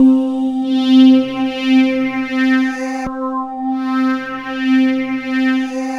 Index of /90_sSampleCDs/USB Soundscan vol.13 - Ethereal Atmosphere [AKAI] 1CD/Partition E/11-QUARTZ